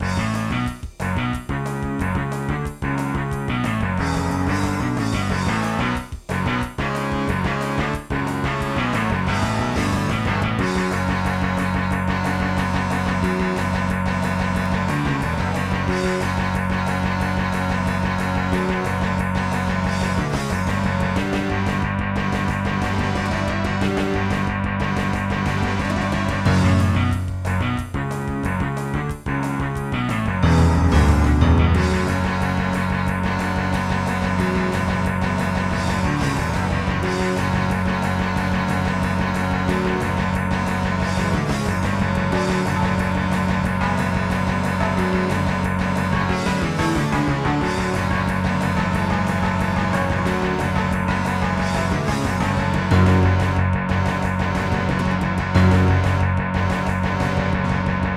Music: GM midi
Gravis Ultrasound PNP (external player and utopia)
* Some records contain clicks.